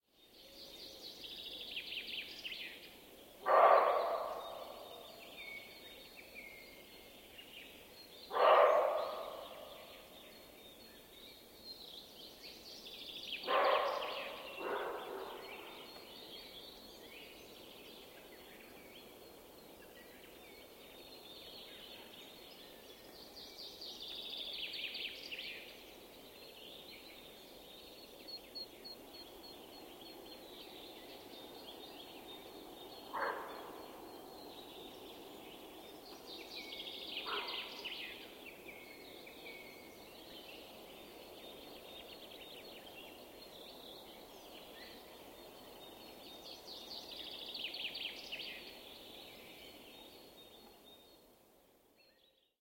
На этой странице собраны натуральные звуки косули — от нежного фырканья до тревожных криков.
Звуки косули в дикой природе записанные издалека